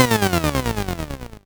lose-a.ogg